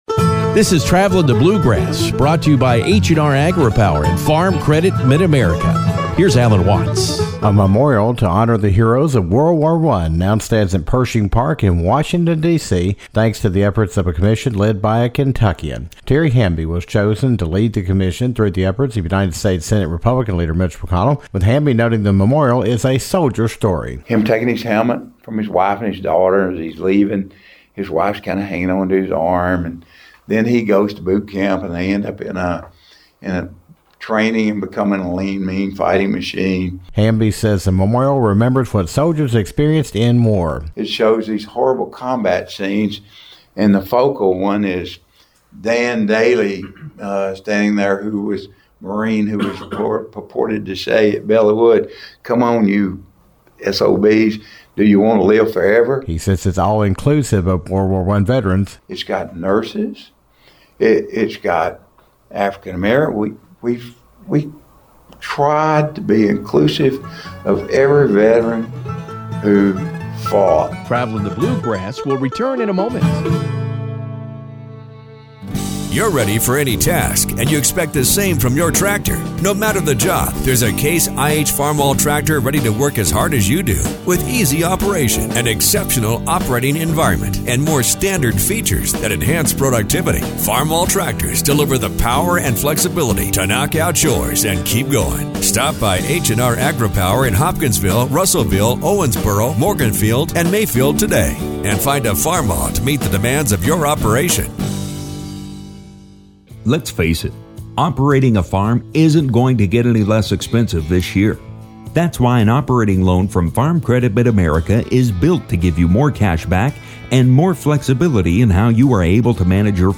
World War I Memorial Commission Chairman Terry Hamby, who is from Kentucky, says the memorial that is now open in Pershing Park in Washington, DC, provides proper honor to the soldiers who fought in the war. Hamby talks about the memorial, how it shares “A Soldiers Story”, and accomplishes the mission of honoring and remembering those who fought and died in the war.